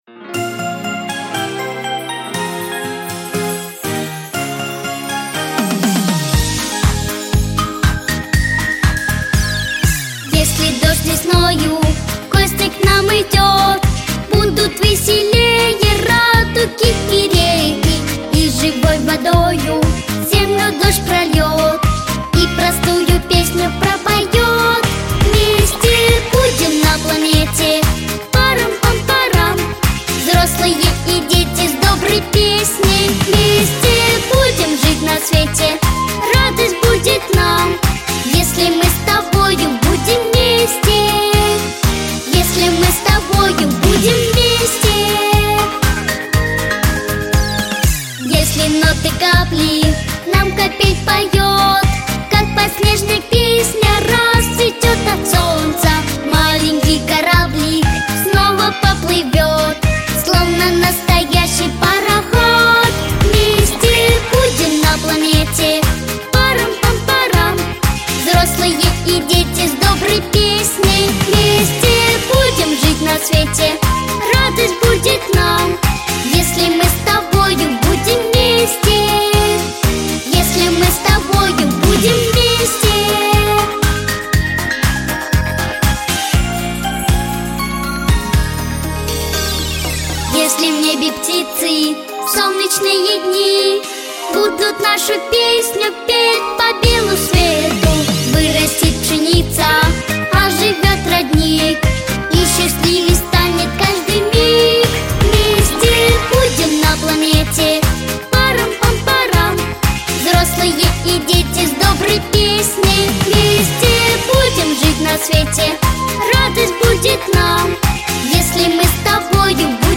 • Категория: Детские песни
Слушать минус
караоке